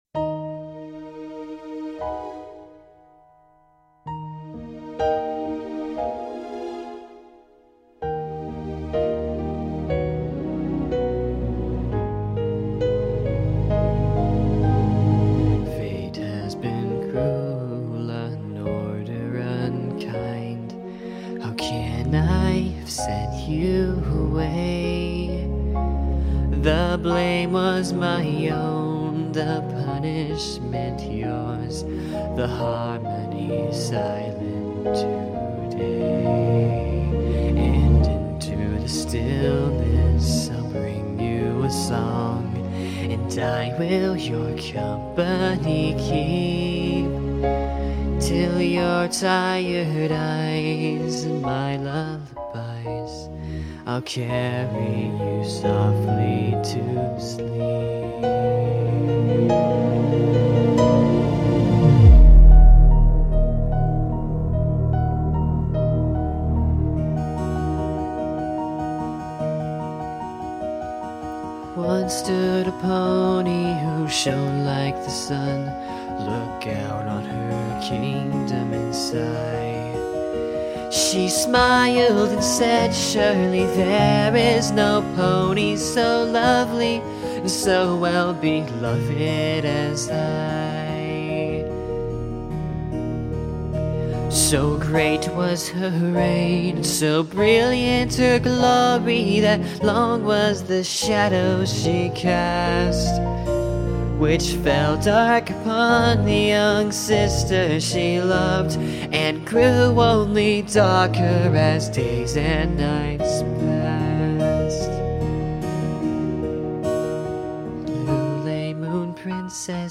This is my best attempt at singing this song.